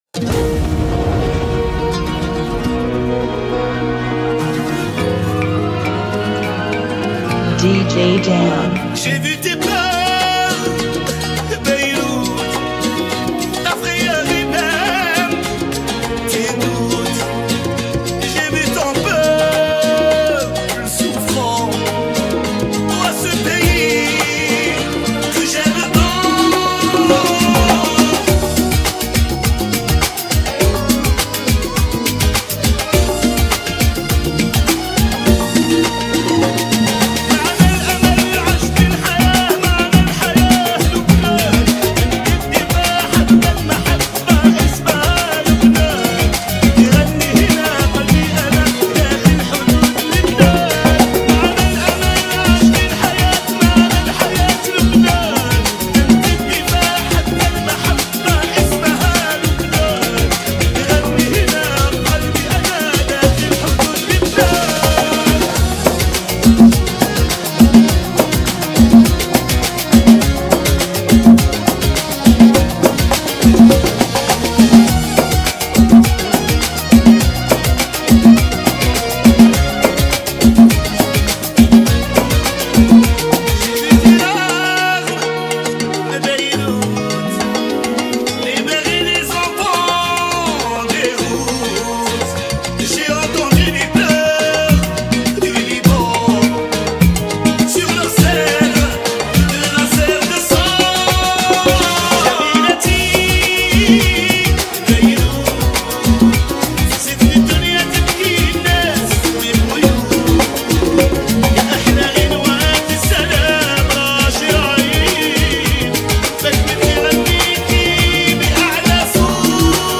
102 BPM
Genre: Salsa Remix